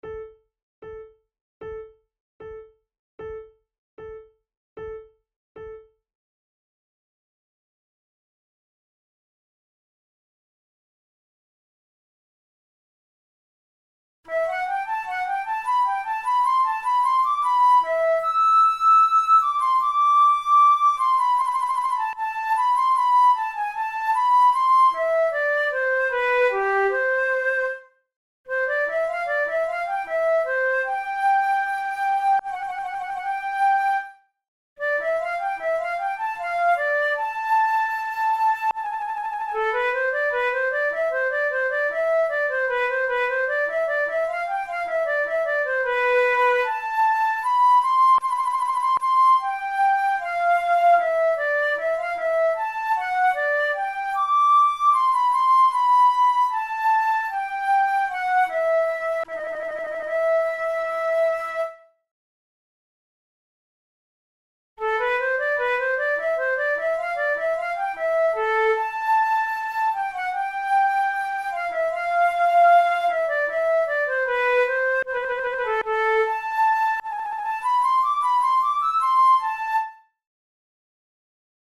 KeyA minor
Tempo76 BPM
Baroque, Sonatas, Written for Flute